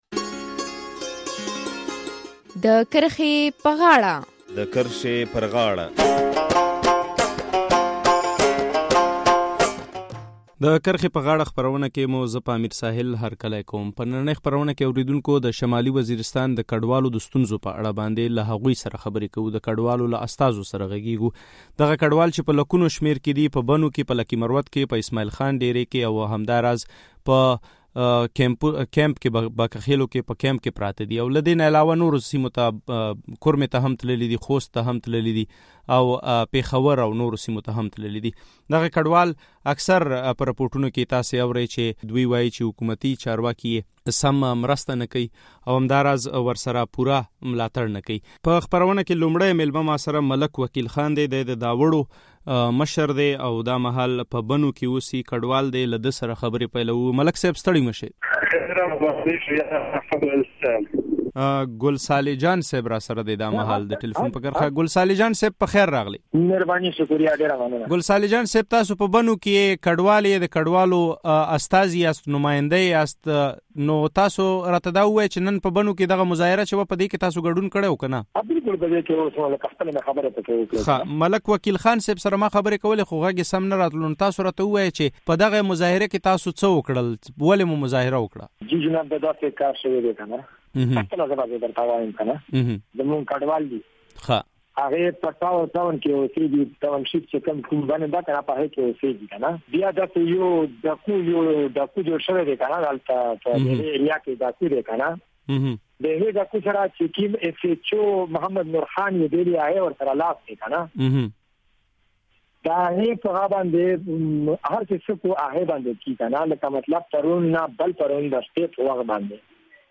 په نننۍ کې خپرونه کې مو په بنو کې د شمالي وزیرستان د کډوالو له مشرانو سره خبرې کړې دي. دوی وايي چې د پاکستان تحریک انصاف حکومت په اسلام اباد کې د احتجاجي ناستو له پیل راهیسې دوی او د دوی ستونزې هېرې کړې دي او هېڅ حکومتي چارواکي ورسره مرستې نه کوي.